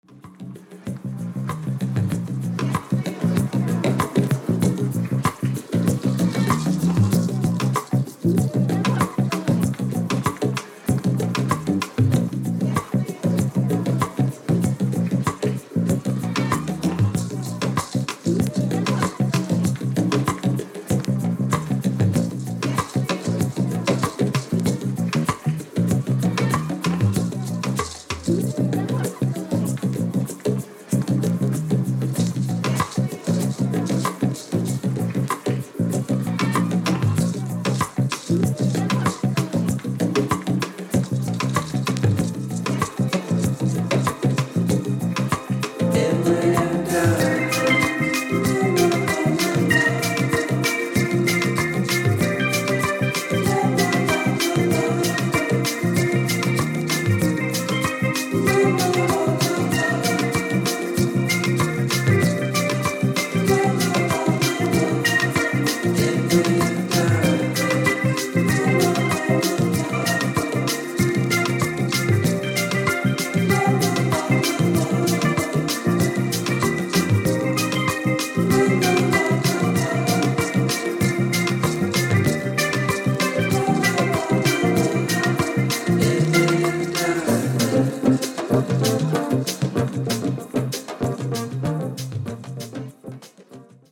デトロイトの人とは思えないバレアリック感漂う